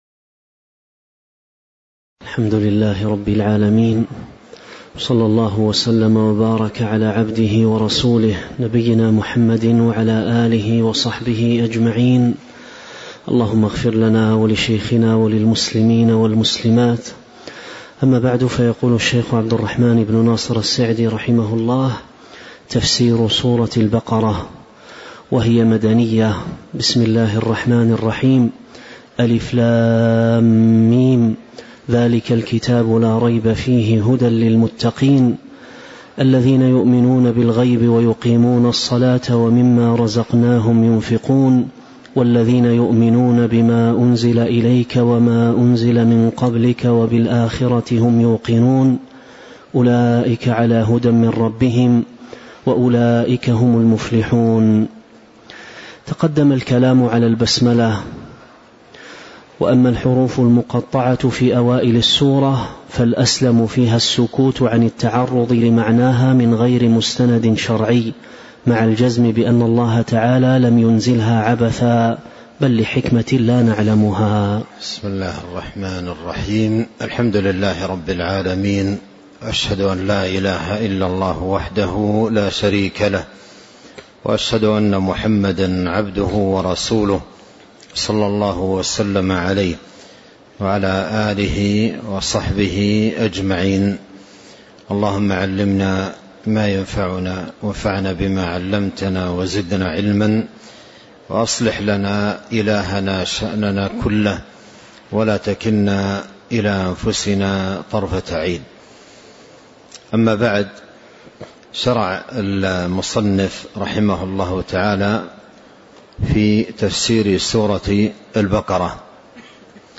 تاريخ النشر ٢١ ربيع الأول ١٤٤٦ هـ المكان: المسجد النبوي الشيخ